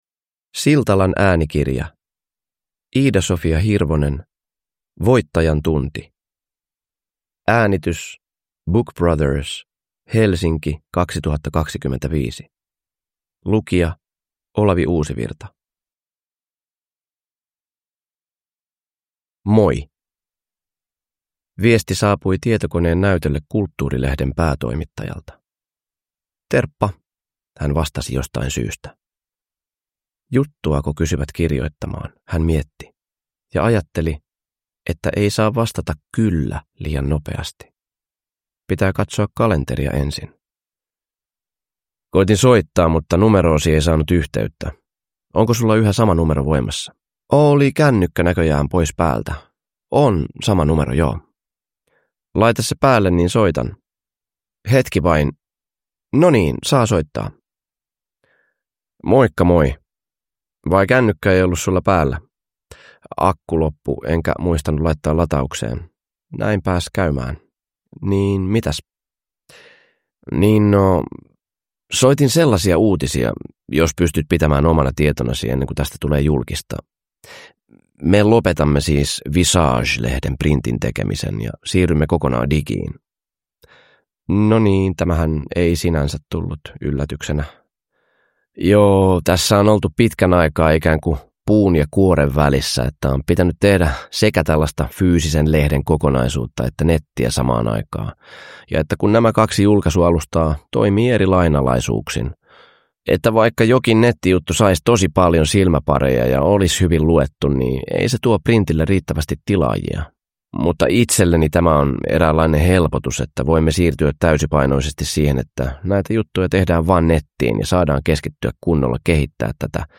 Voittajantunti – Ljudbok
Uppläsare: Olavi Uusivirta